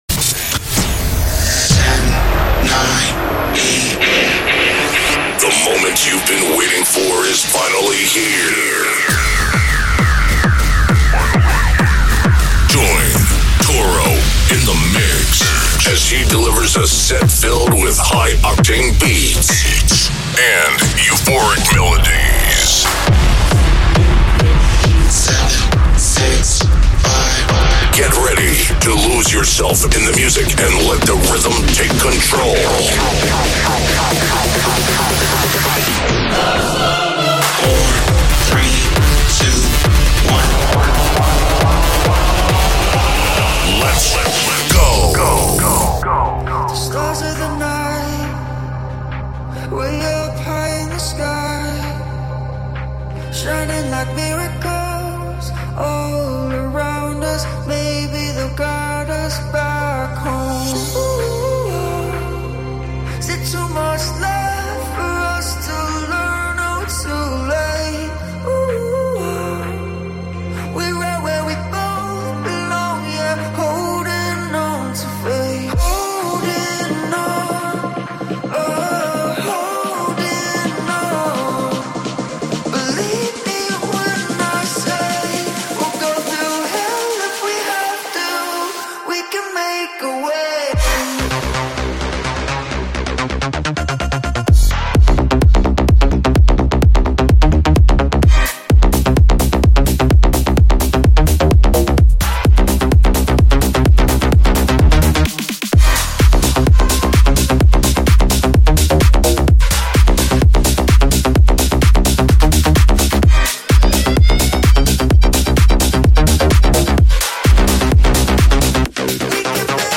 " the ultimate dance and trance music podcast.